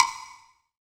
HWOODBLHI.wav